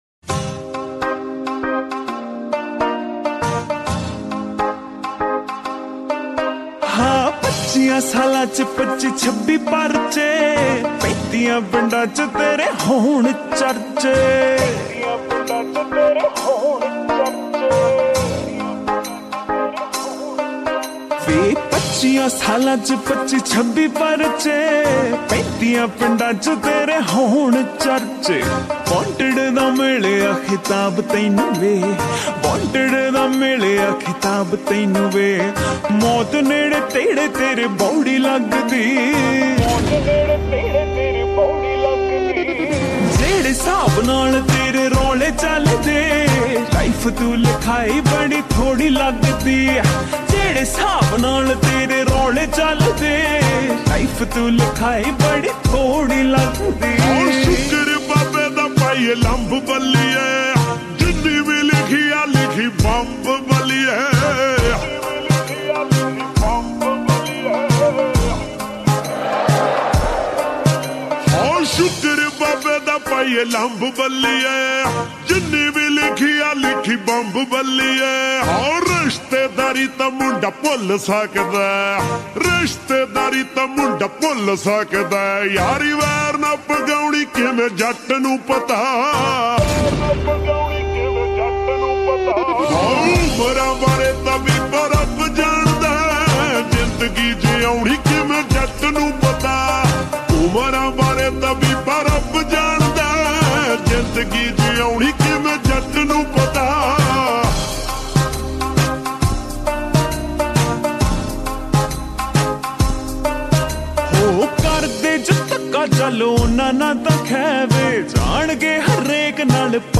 slow reverb Punjabi song